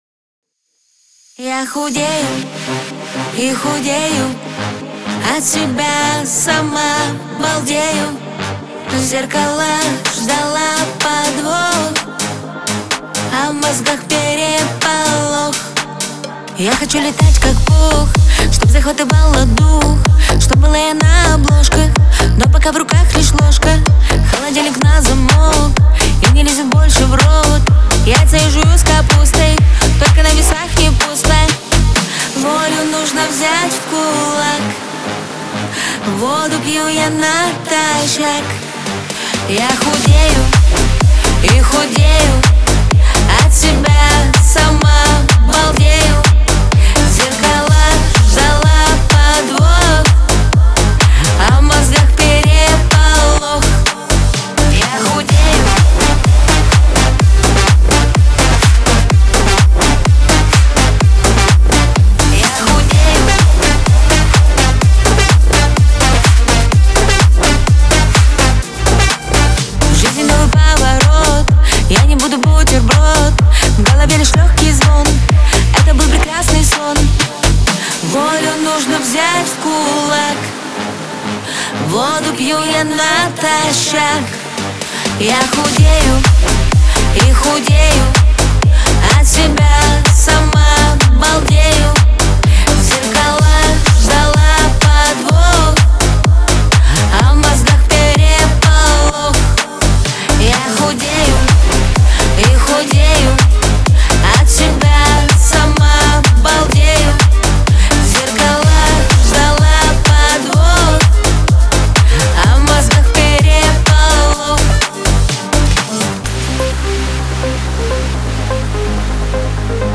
Главная » Файлы » Аранжировки